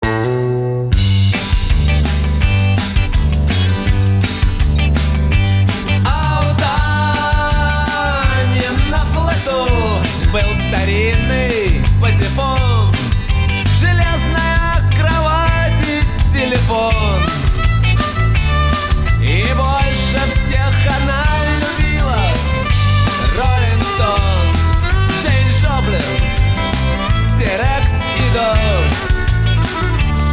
80-е
русский рок